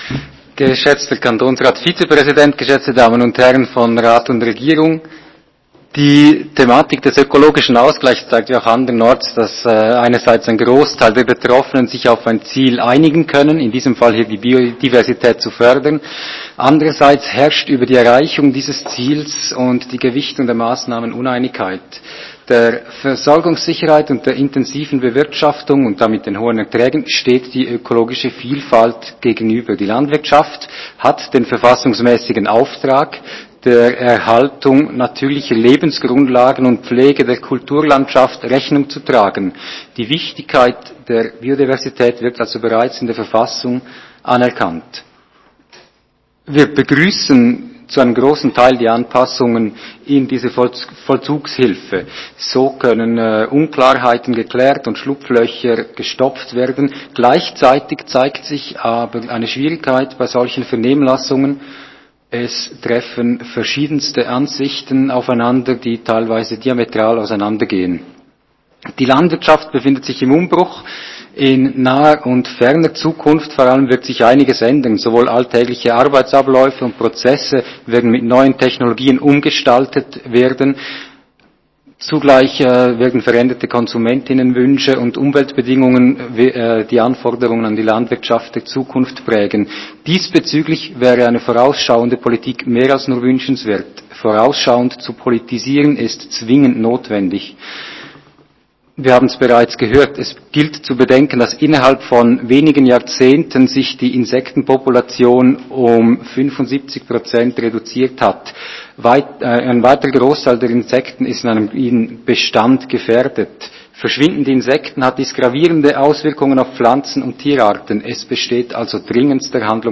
20.9.2021Wortmeldung
Session des Kantonsrates vom 20. bis 22. September 2021